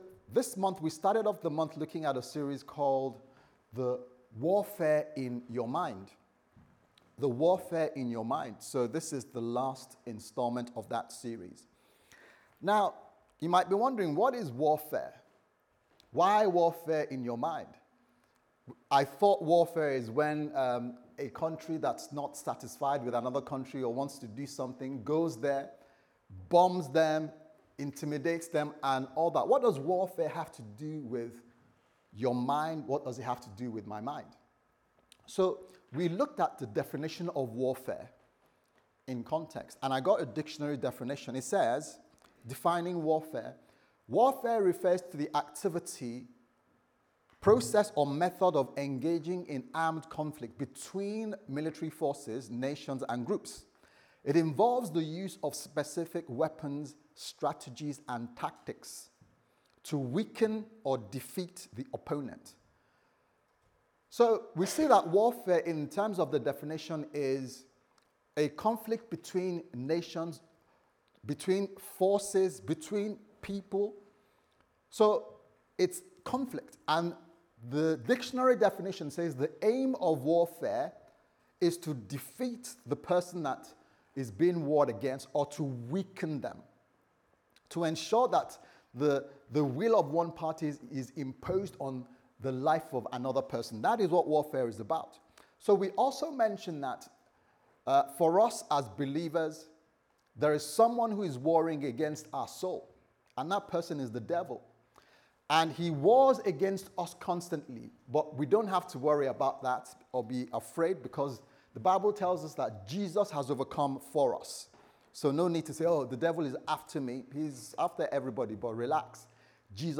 The Warfare In Your Mind Service Type: Sunday Service Sermon « The Warfare In Your Mind